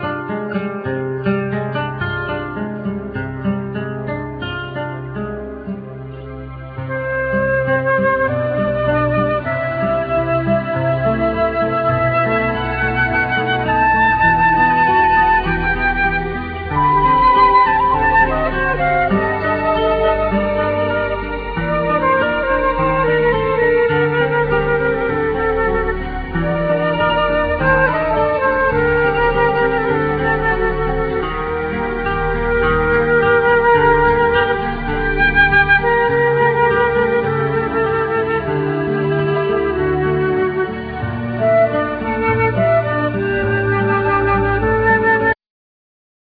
other musicians   vocals
guitar
bass
percussion,samples
synth,drums,udo
flute